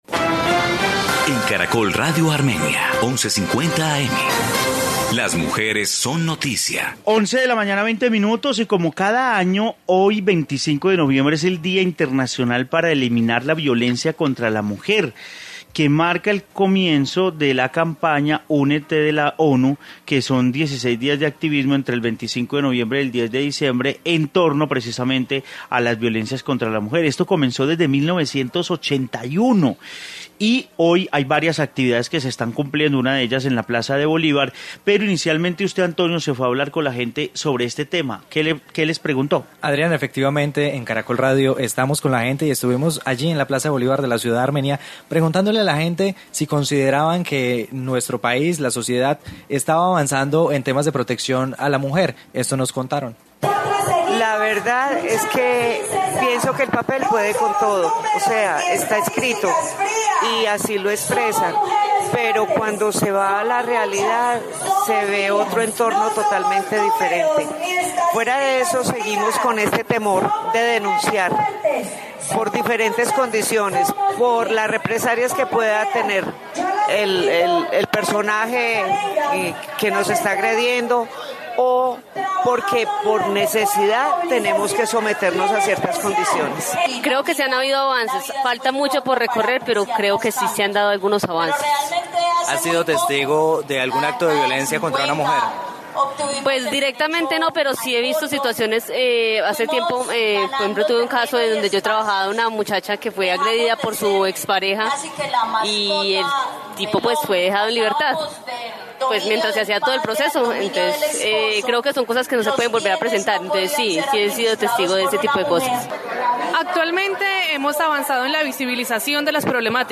Informe violencia contra la mujer en Armenia